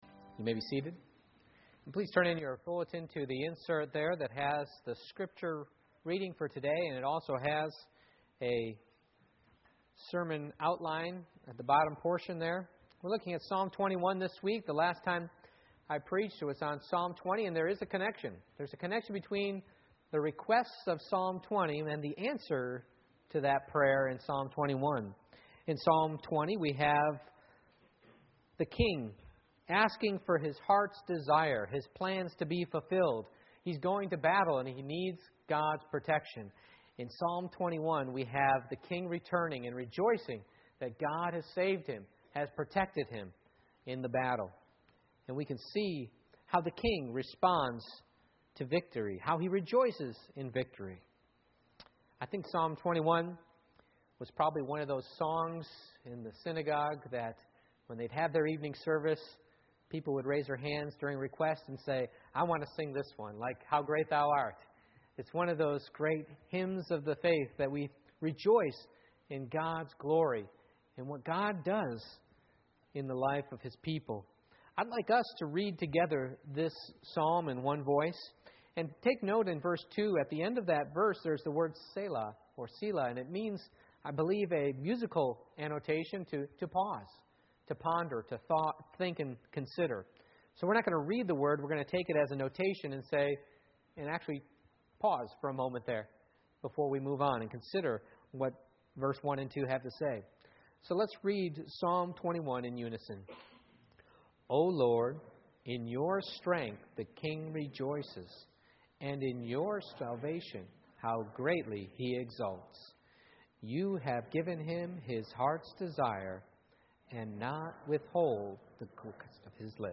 Psalm 21:1-13 Service Type: Morning Worship How We Handle Victory Reveals What's In Our Heart Who get's the credit?